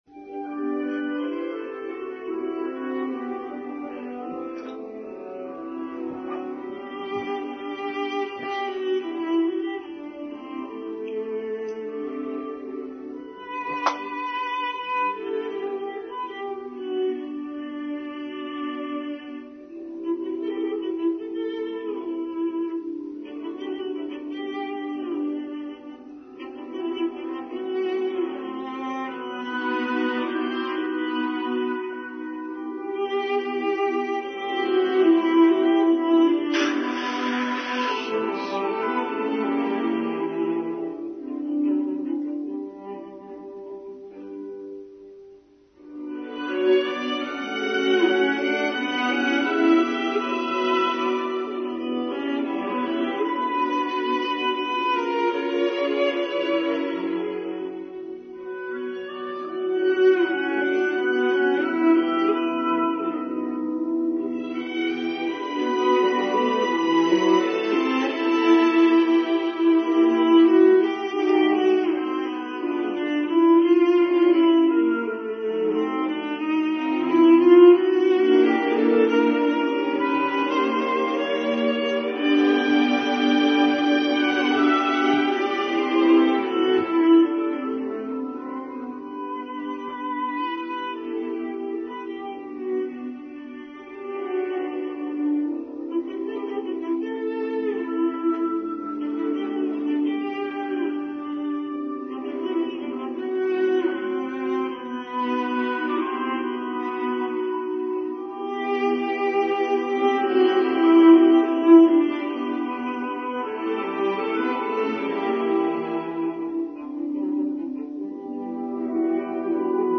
The Unpredictability of Life: Online Service for 2nd June 2024
Prelude Chanson de Matin by Edward Elgar